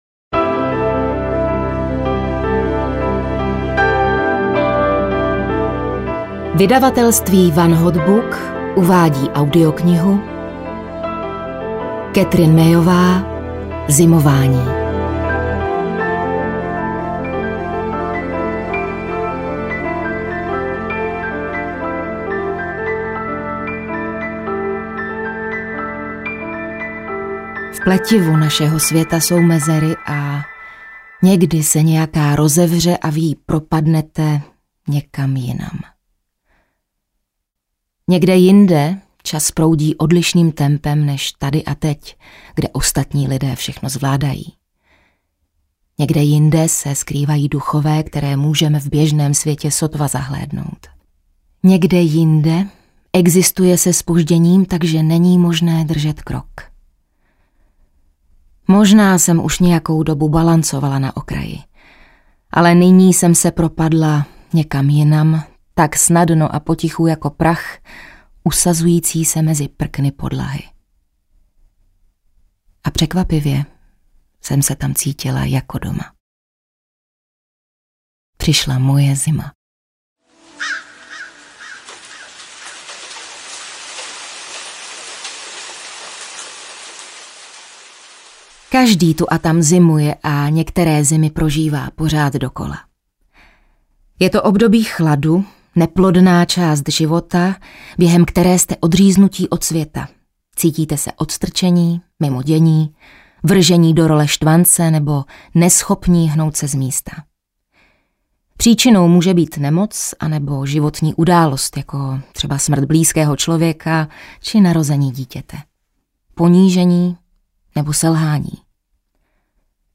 Zimování audiokniha
Ukázka z knihy